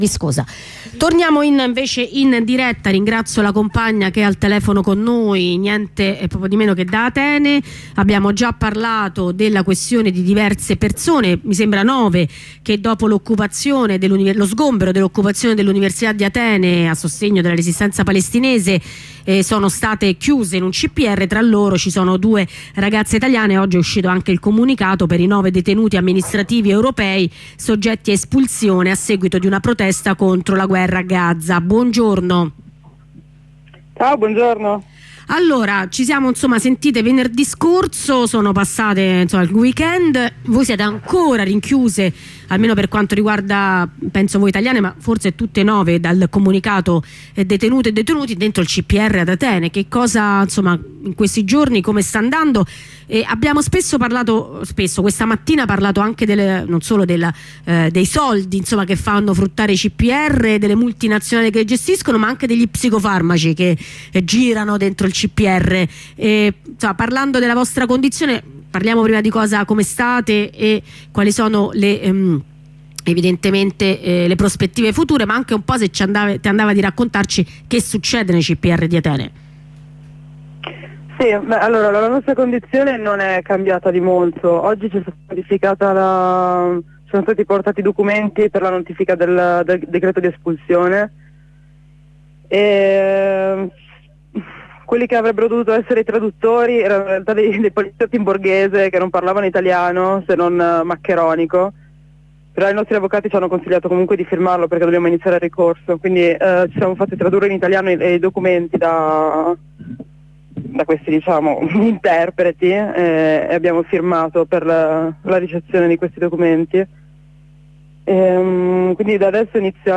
Una compagna da dentro il CPR